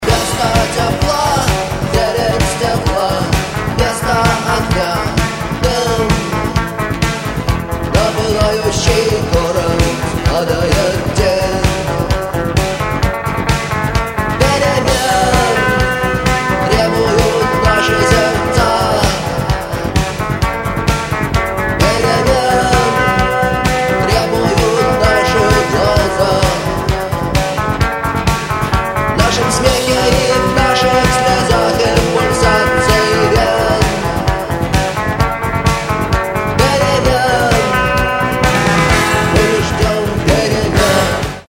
• Качество: 128, Stereo
классика русского рока